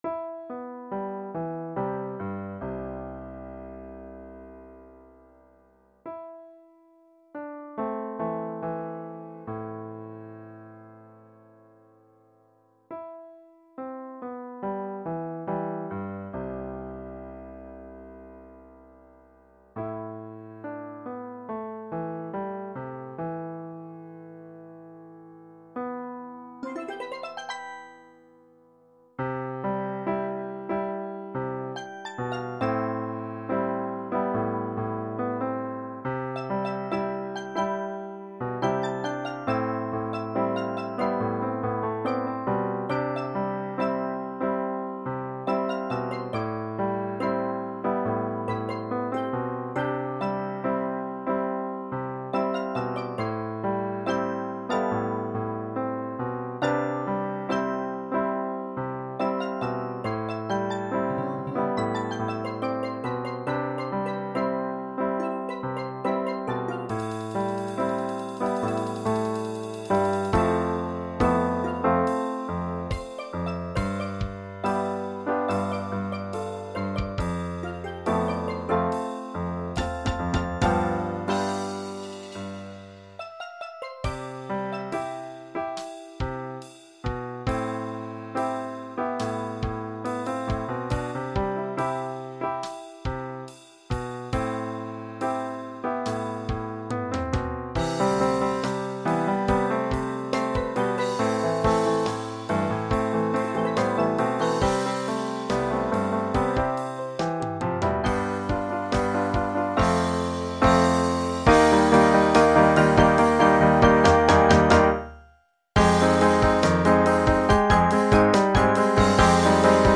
» Steel Drum Sheet Music
» Calypso & Soca